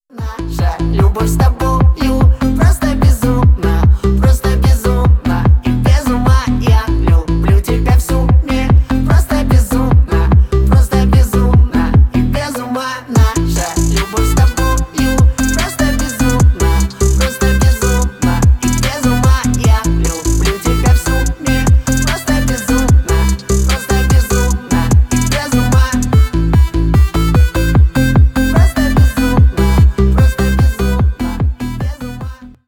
Поп Музыка
весёлые
милые